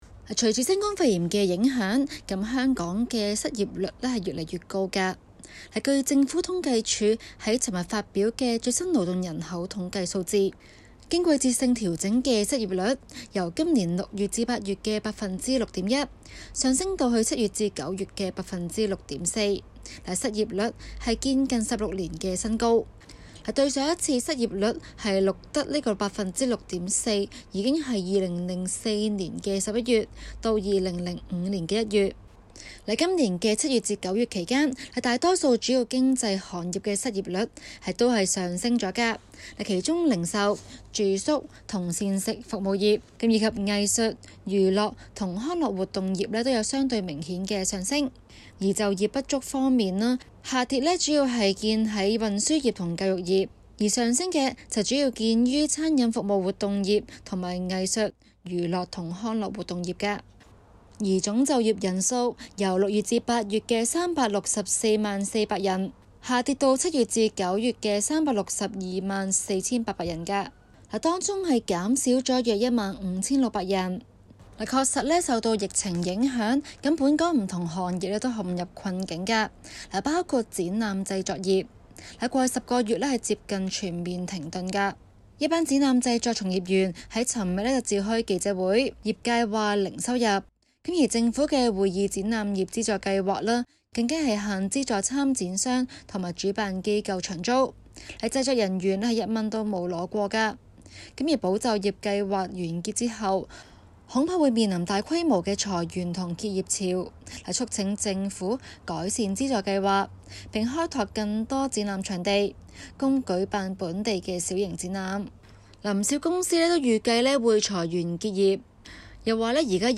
今期【中港快訊】報導香港失業率創下16年新高， 究竟情況如何？